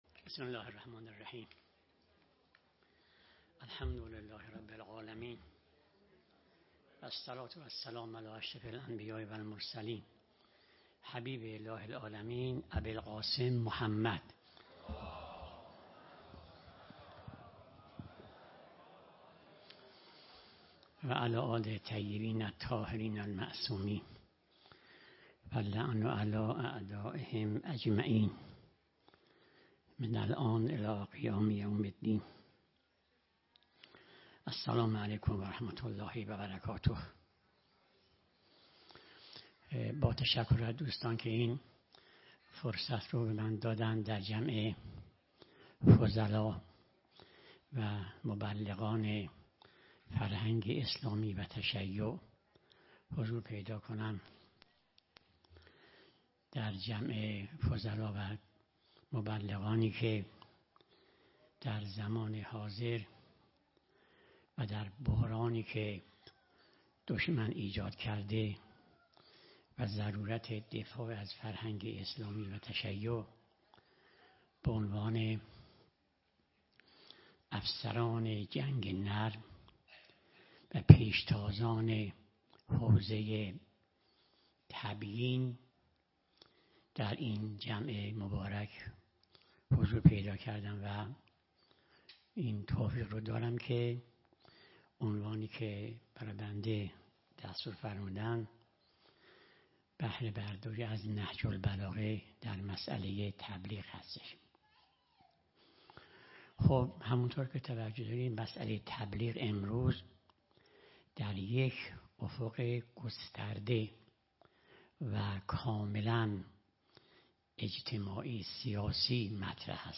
سخنرانی
در سلسله نشست های طلیعه رمضان 1447ه.ق